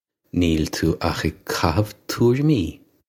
Pronunciation for how to say
Nyeel too och ig ka-hiv too-rim-ee
This is an approximate phonetic pronunciation of the phrase.